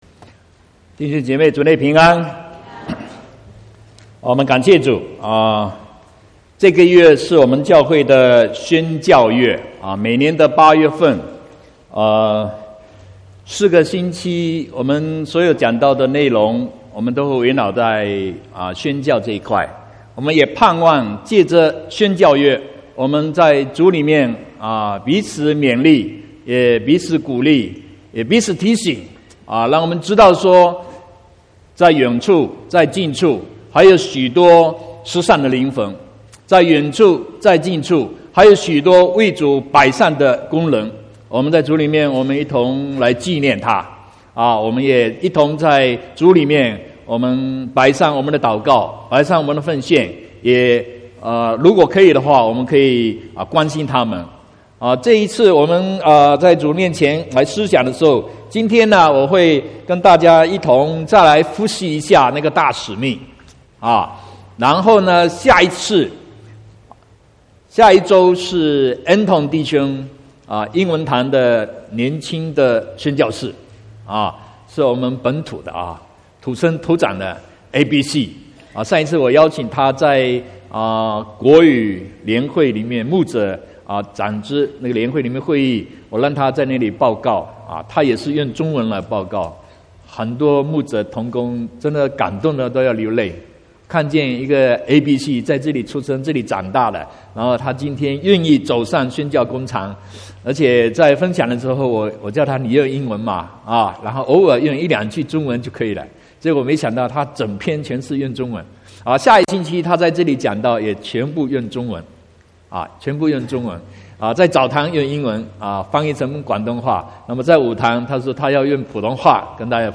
12/8/2018 國語堂講道